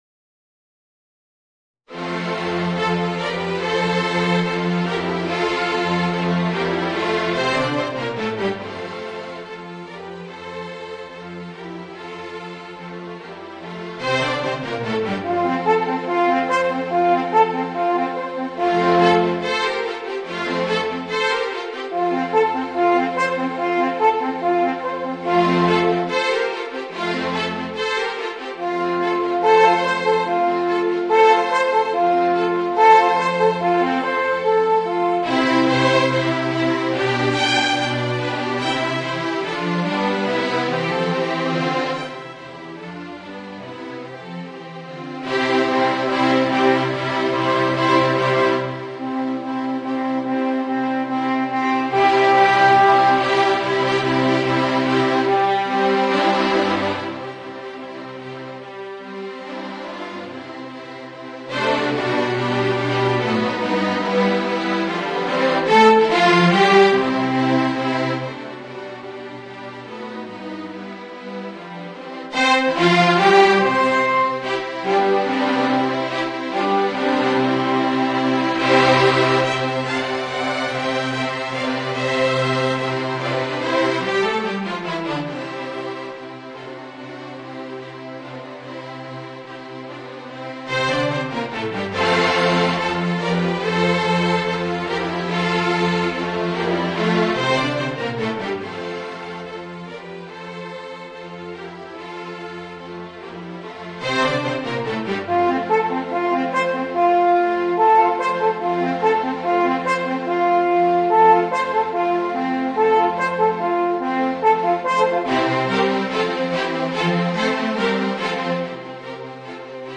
Voicing: Alphorn and String Orchestra